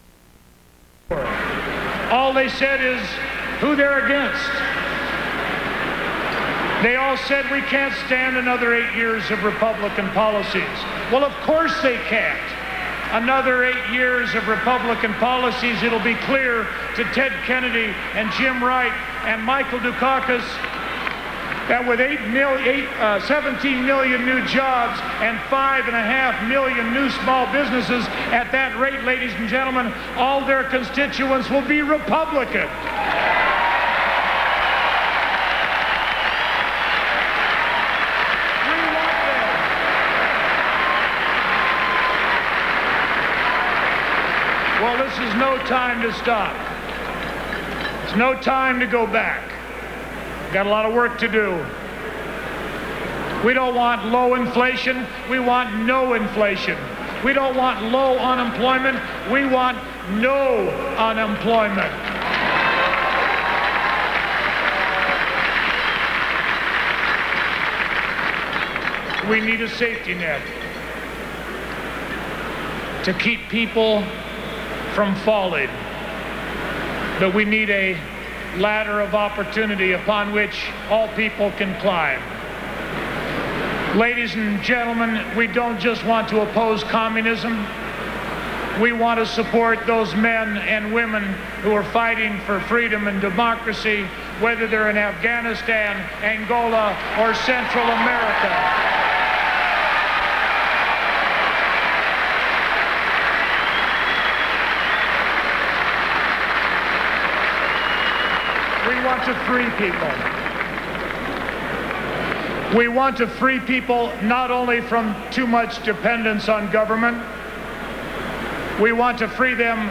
George Bush addresses teachers on the subject of his promise to become the "education president"